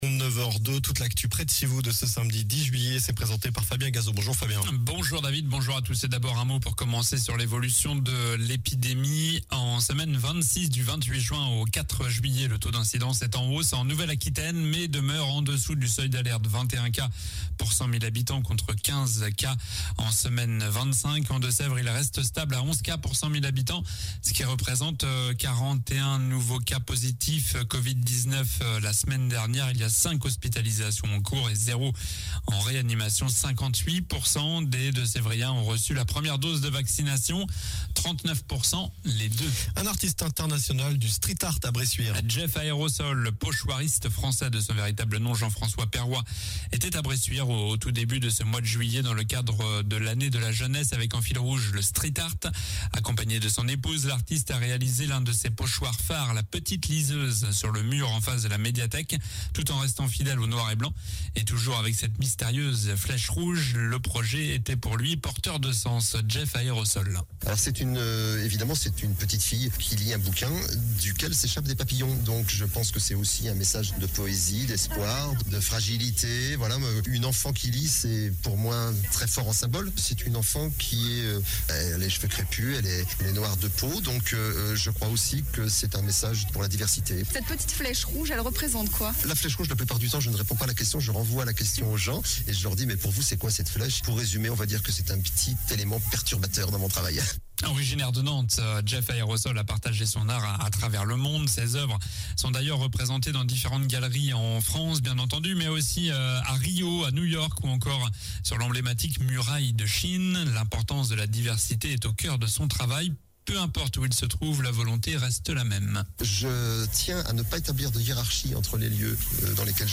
Journal du samedi 10 juillet (matin)